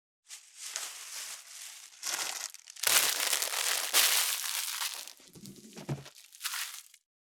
648コンビニ袋,ゴミ袋,スーパーの袋,袋,買い出しの音,ゴミ出しの音,袋を運ぶ音,
効果音